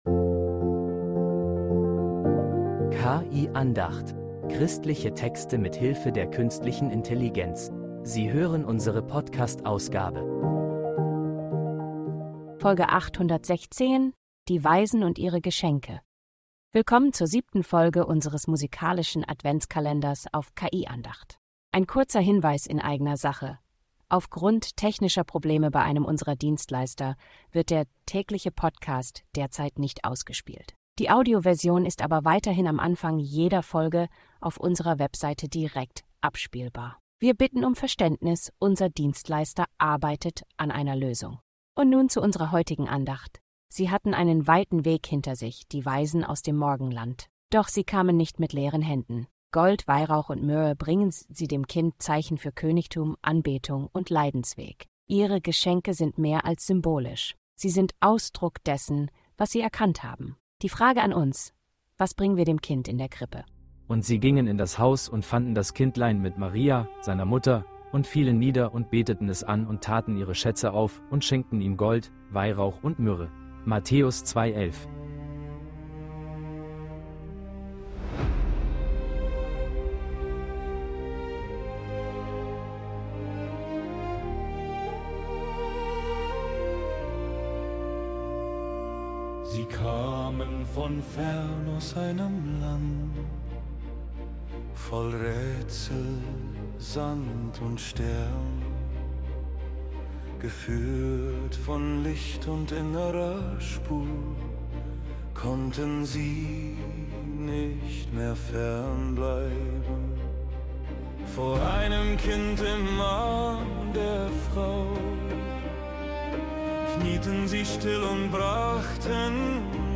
Diese musikalische Andacht fragt dich: Was bringst du dem Kind in